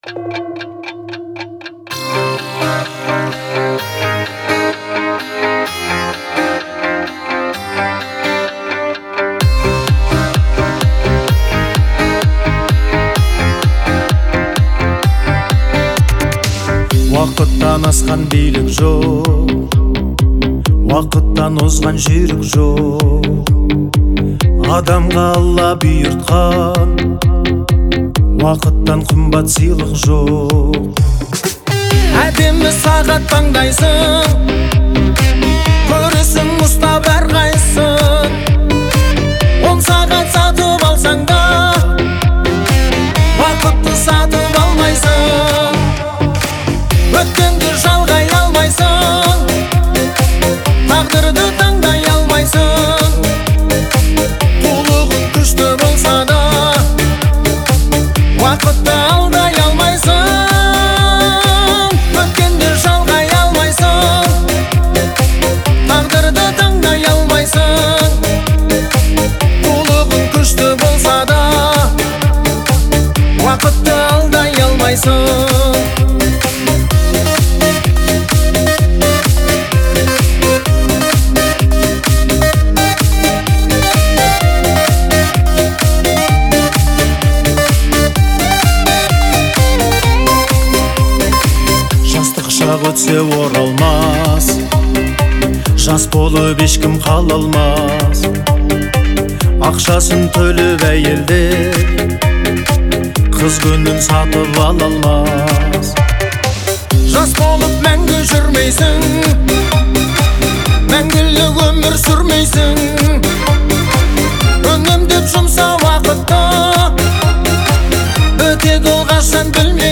который сочетает в себе элементы поп и народной музыки.